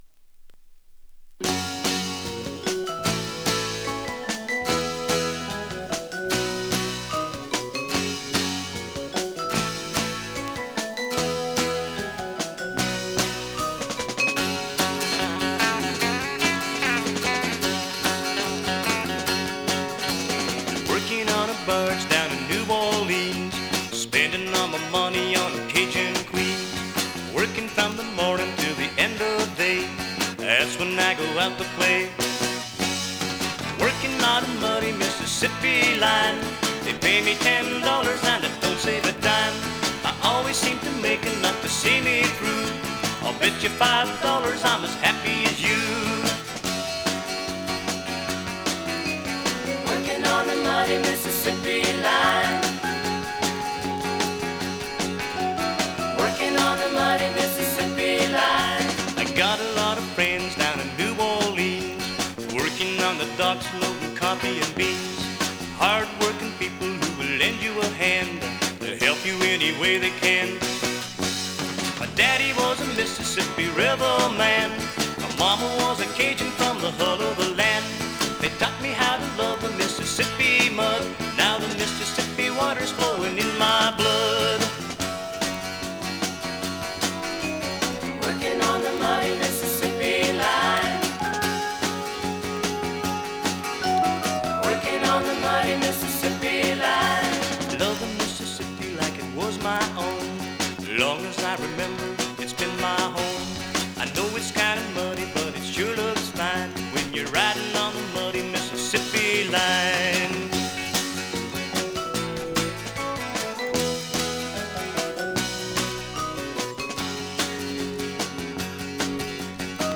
vibes, piano and organ